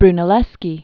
(brnə-lĕskē), Filippo 1377-1446.